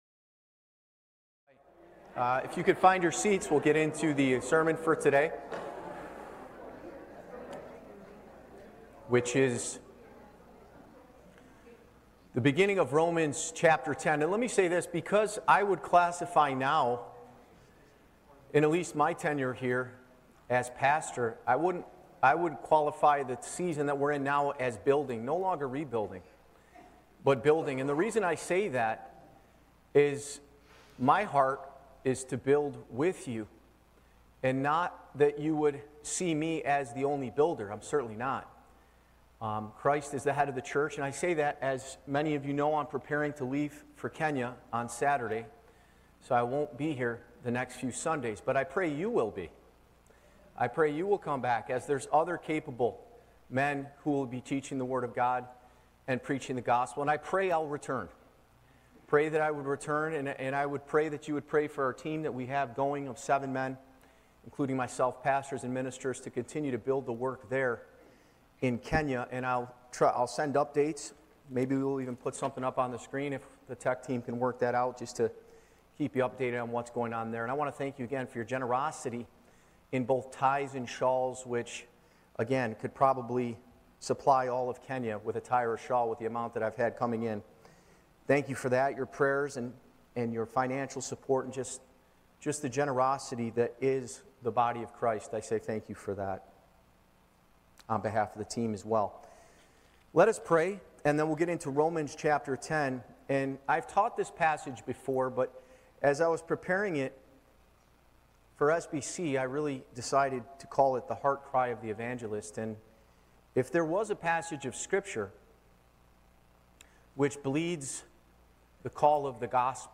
Church Location: Spencerport Bible Church
Live Recording